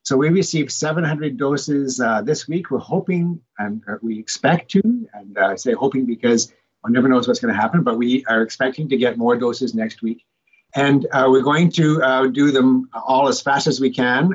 Dr. Ian Gemmill, Medical Officer of Health for HKPR said at Wednesday’s HKPR virtual media scrum the first doses of the Moderna vaccine have arrived in the area and the first order of business is to begin vaccinating those in long term care homes.